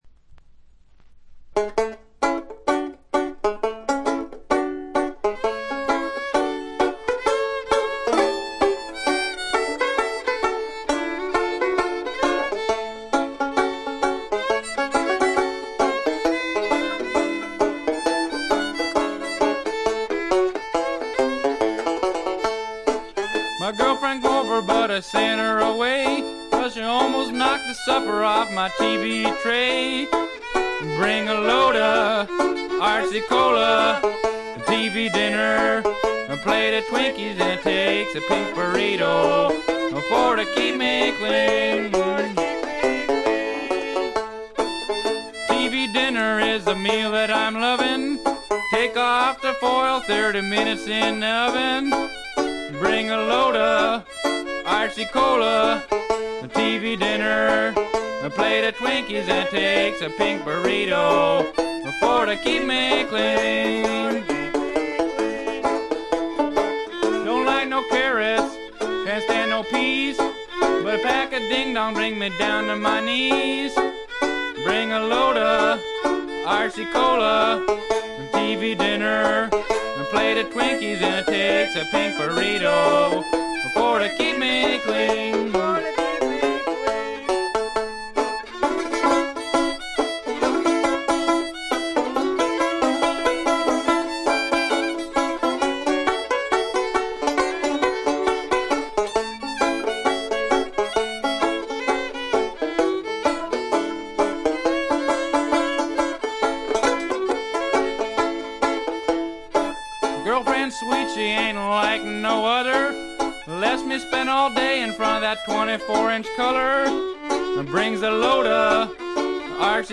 ところどころでチリプチ少々、散発的なプツ音少々。
戦前のブルース、ジャズ、ラグ、ストリングバンドといった世界をどっぷりと聴かせてくれます。
文字通りのチープで素人くささが残る演奏が愛すべき作品です。
試聴曲は現品からの取り込み音源です。